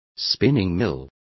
Also find out how hilanderias is pronounced correctly.